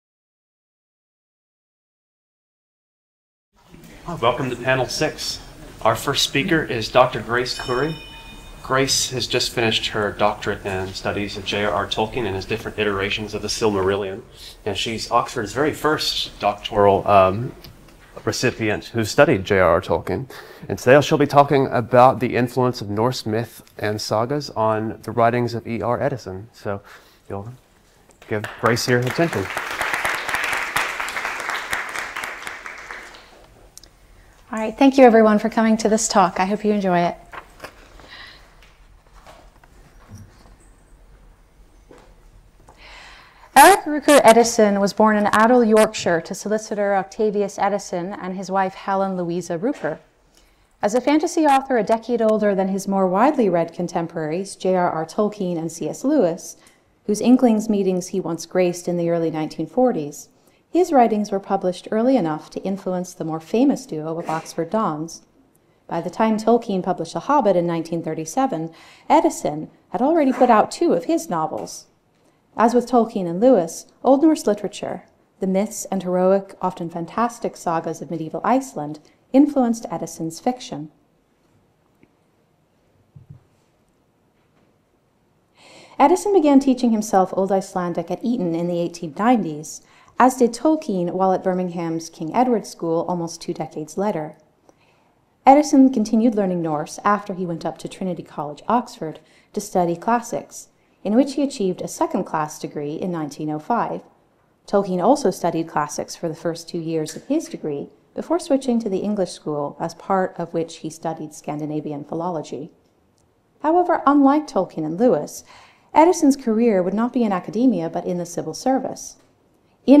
Short talk (10 minutes) - Part of the Bloomsbury-Oxford Summer School (23rd-25th September 2025) held at Exeter College.